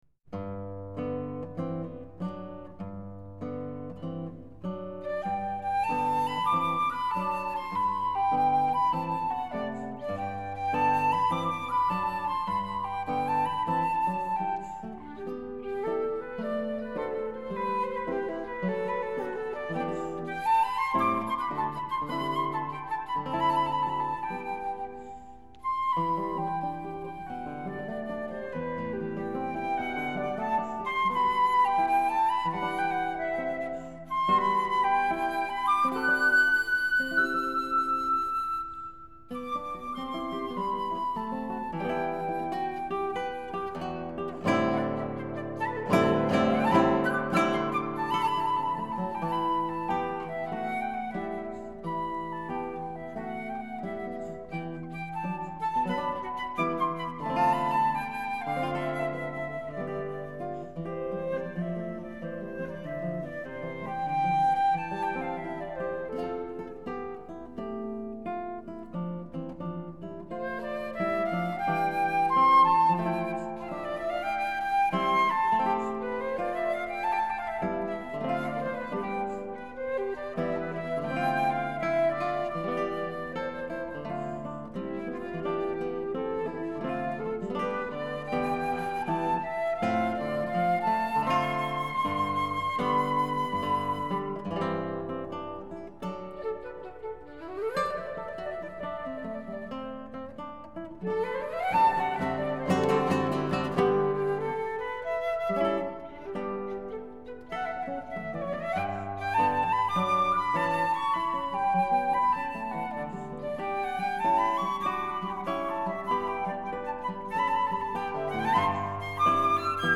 * Scored for flute & viola  /  ** Scored for flute & guitar